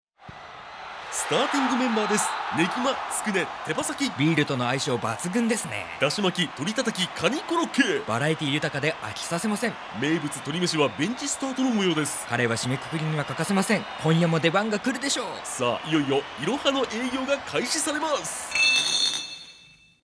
ラジオCM
当事者の語りかけることばにとてもリアリティがあったのと、それぞれの表現がとてもわかりやすく想像しやすく、自分もやさしい一歩を踏み出そうと思いました。